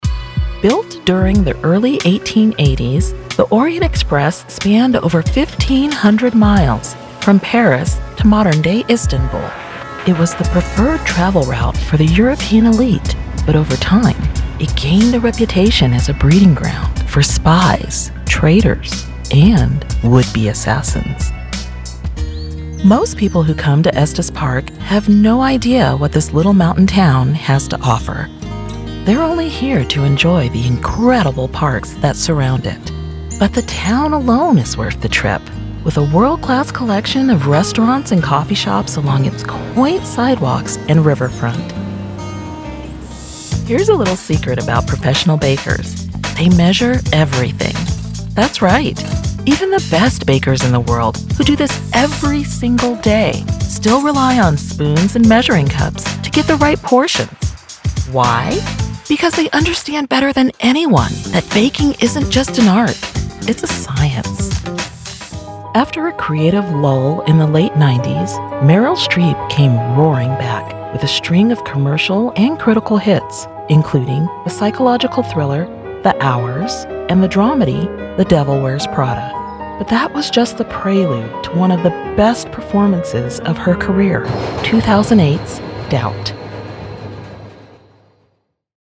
Narrative & Audiobooks
Clear, engaging, and professional narration for long-form content, documentaries, and immersive literary experiences.
Every audition and final track is recorded in professional-grade studios for pristine clarity.